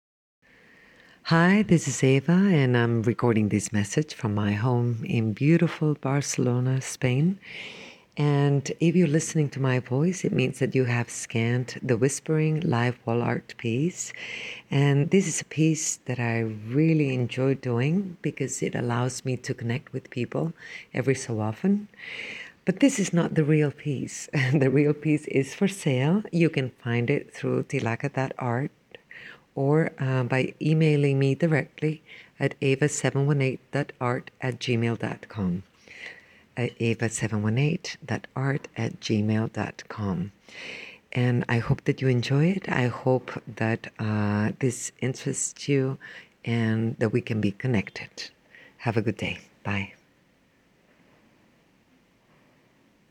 whispering.mp3